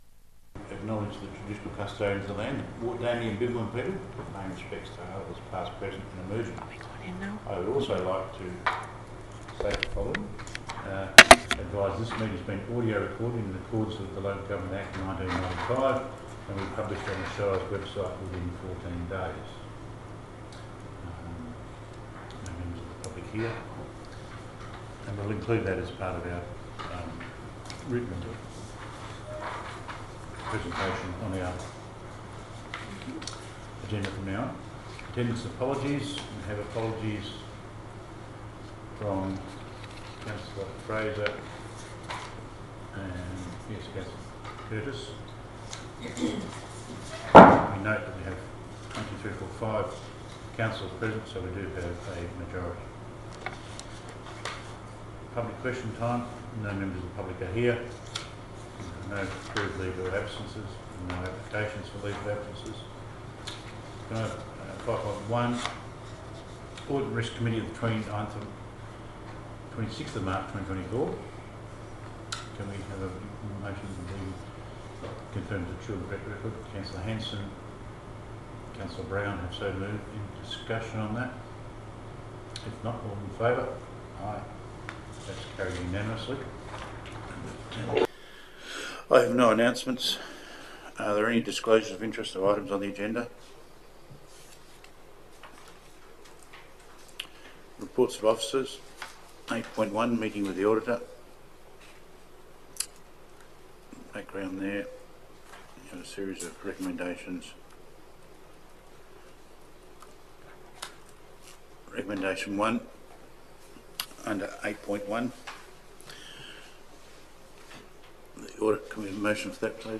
Location: Council Chambers, 15 Adam Street, Nannup